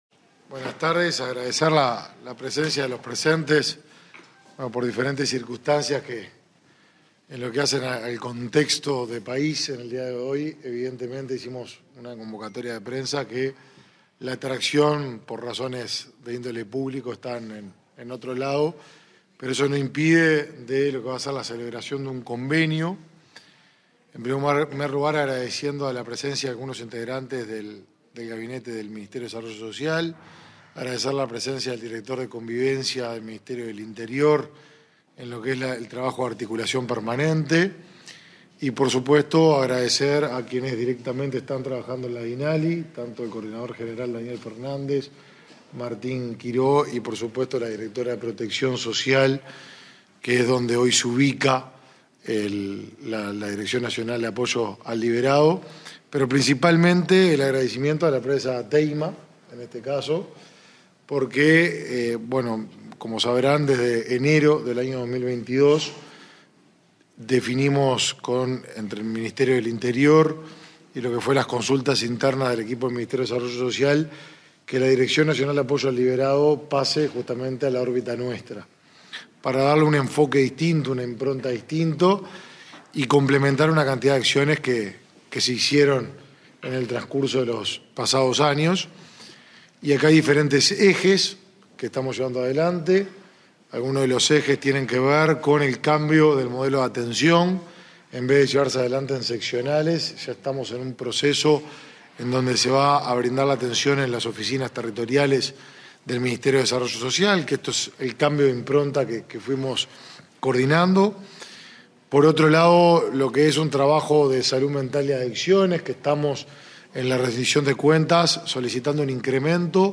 Palabras del ministro de Desarrollo Social, Martín Lema
Palabras del ministro de Desarrollo Social, Martín Lema 07/07/2022 Compartir Facebook X Copiar enlace WhatsApp LinkedIn Este 7 de julio, la Dirección Nacional de Apoyo al Liberado y la empresa Teyma firmaron un convenio para la generación de nuevas herramientas y oportunidades laborales y educativas para las personas egresadas del sistema penitenciario. El ministro Martín Lema participó del evento.